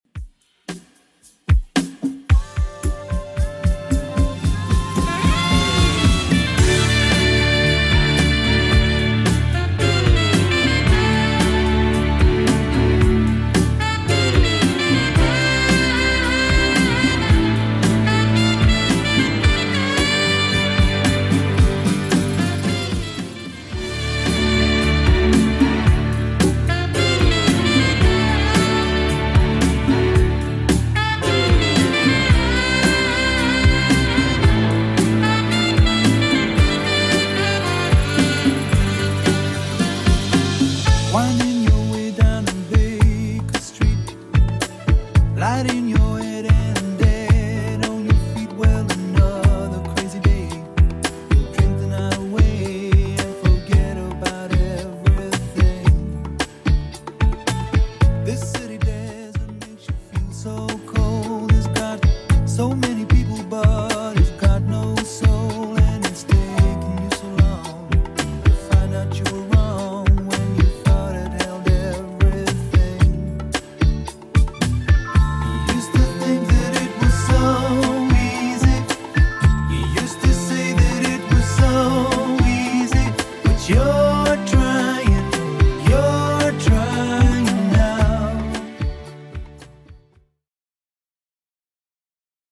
Genre: 90's
BPM: 138